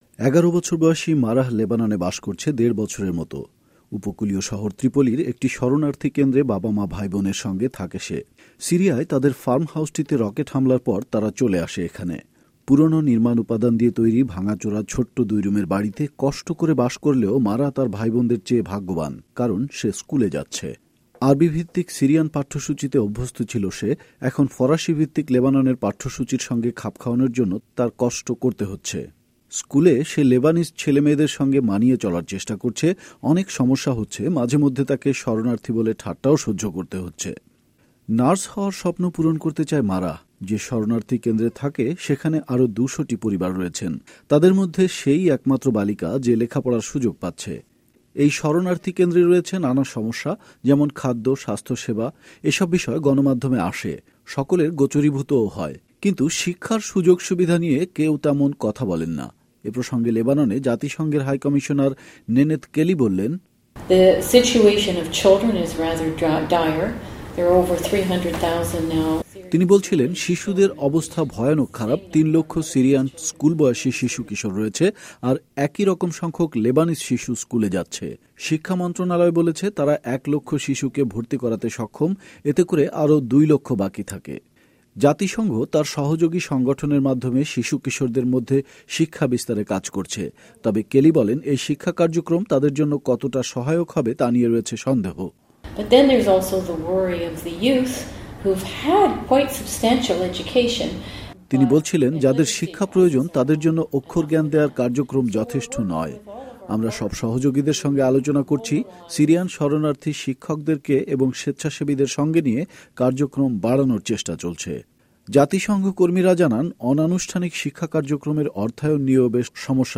বৈরুত থেকে এ বিষয়ে রিপোর্ট করেন